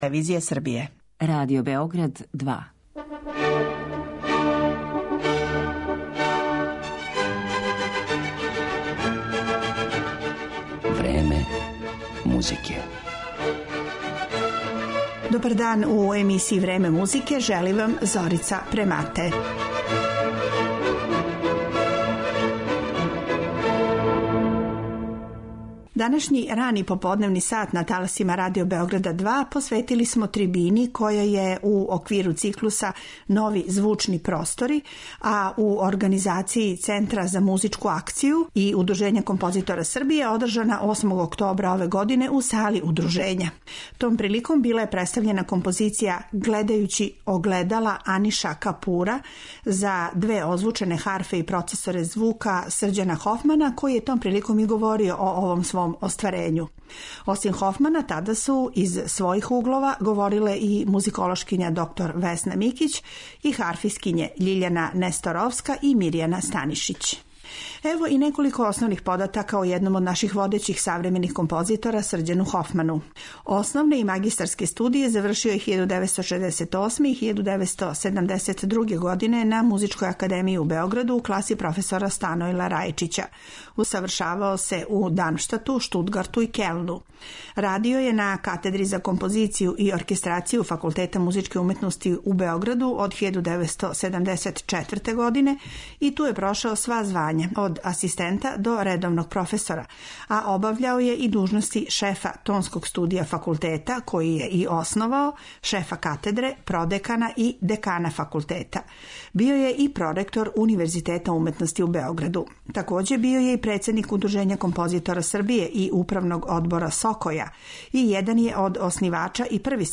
У емисији 'Време музике' емитоваћемо одломке са трибине из циклуса 'Нови звучни простори', која је пре десетак дана одржана у Удружењу композитора Србије.